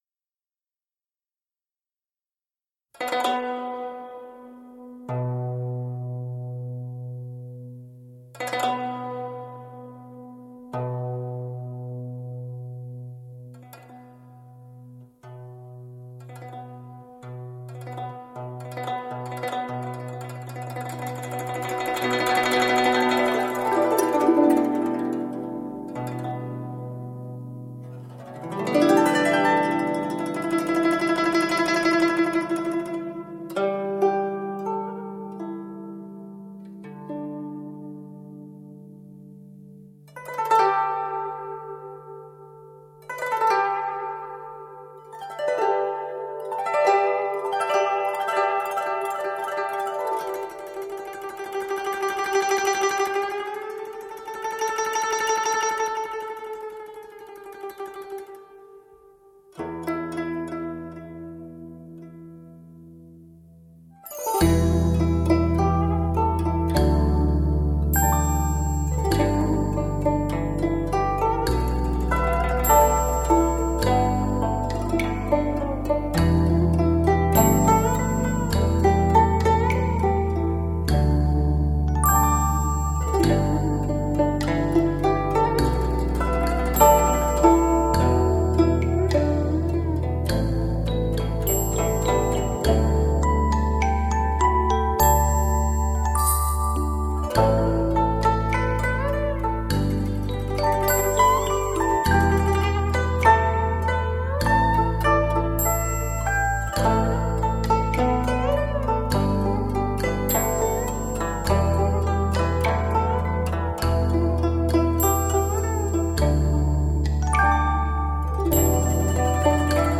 音色柔美而亮丽